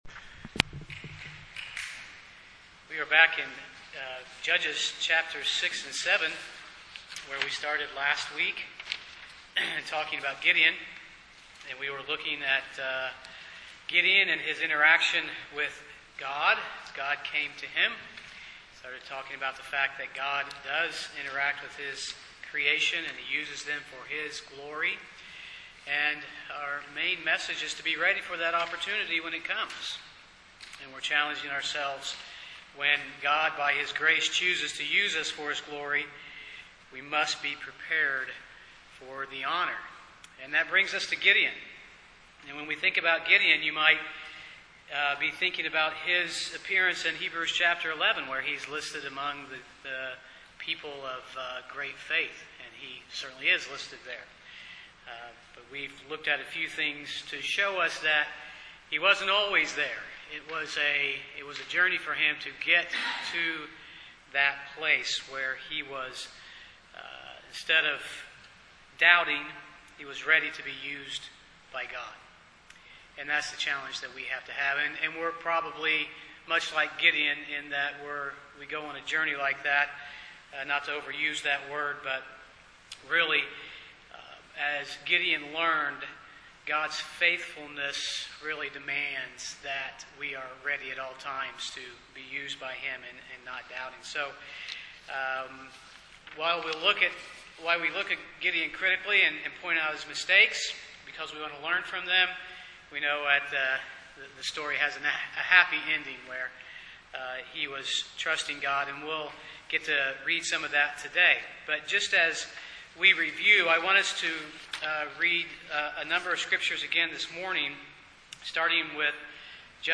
Service Type: Sunday morning Topics: Faith , Gideon , Grace , Service « Ready to be Used by God